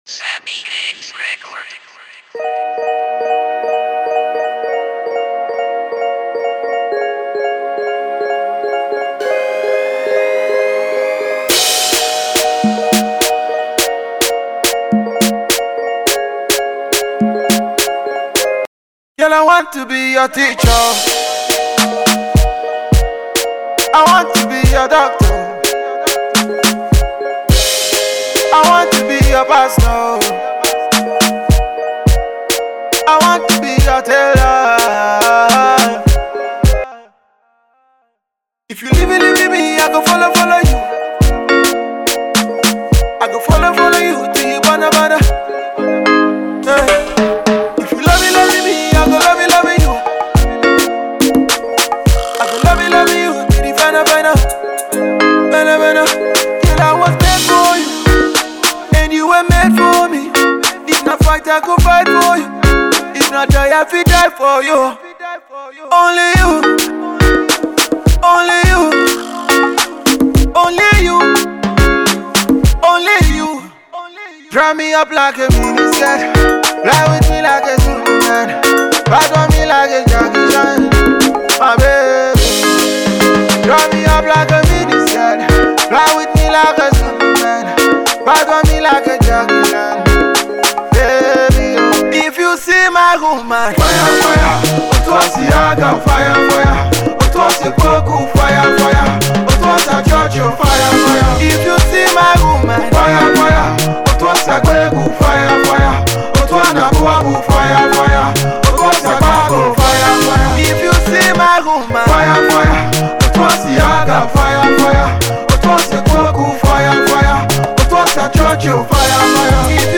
Alternative Pop